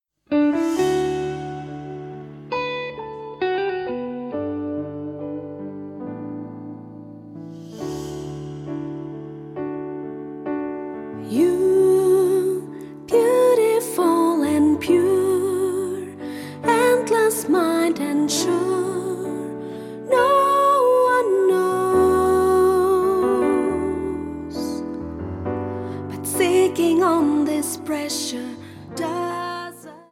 Genre: Soft Rock, Easy Listening.